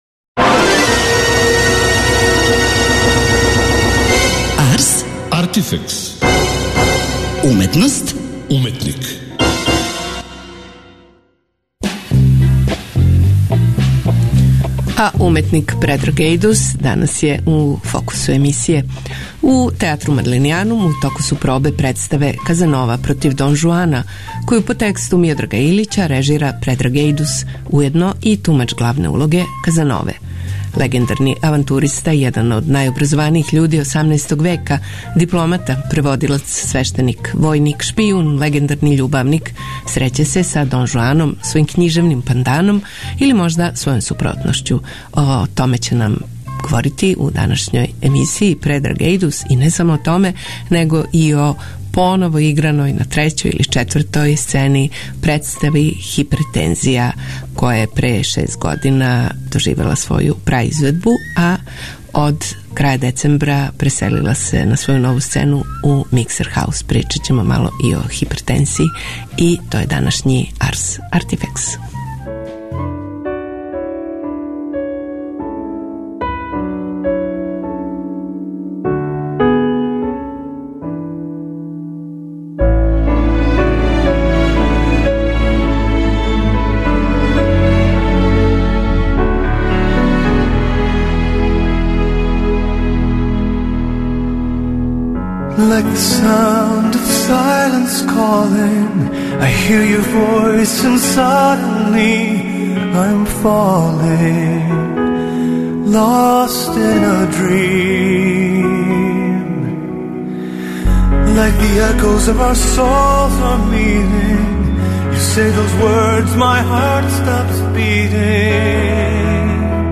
Легендарни авантуриста и један од наjобразованиjих људи 18. века, дипломата, преводилац, свештеник, воjник, шпиjун и легендарни љубавник, среће се са Дон Жуаном, својим књижевним панданом, или можда својом супротношћу - објасниће нам у данашњој емисији наш гост, Предраг Ејдус .